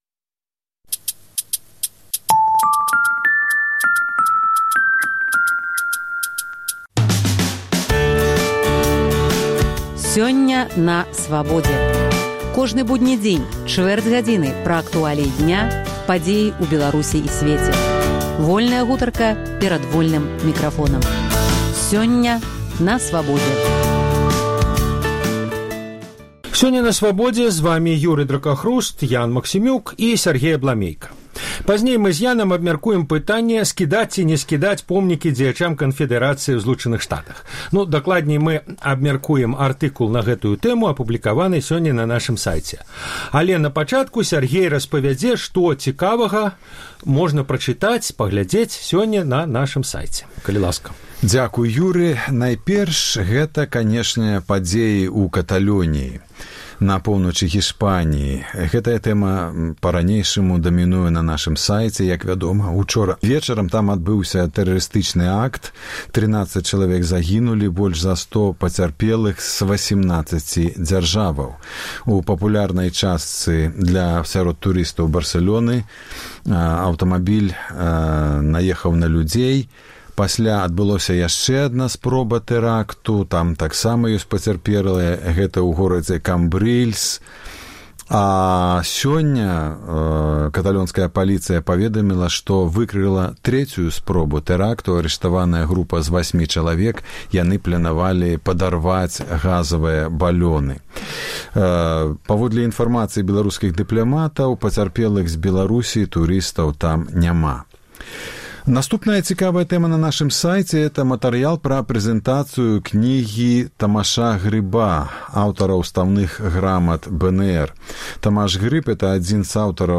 Гутарка на тэмы дня